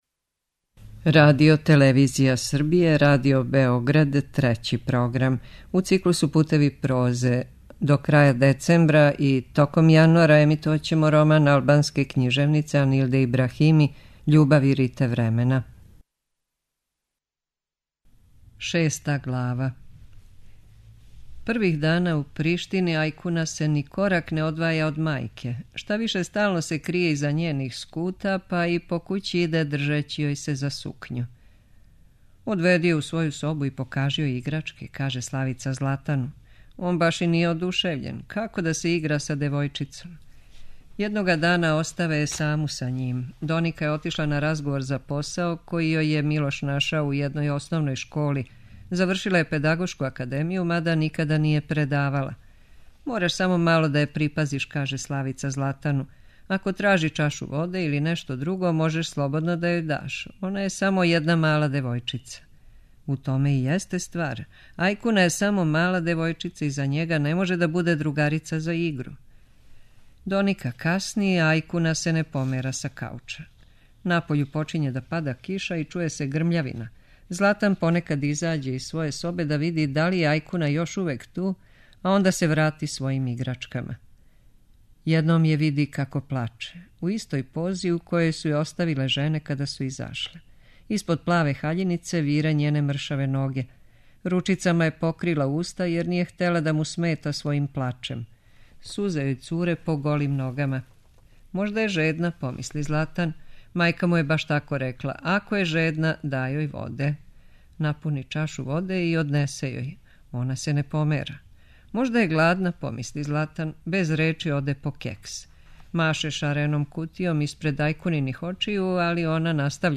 преузми : 7.81 MB Књига за слушање Autor: Трећи програм Циклус „Књига за слушање” на програму је сваког дана, од 23.45 сати.